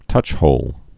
(tŭchhōl)